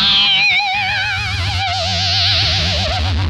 Index of /90_sSampleCDs/Zero G - Funk Guitar/Partition I/VOLUME 001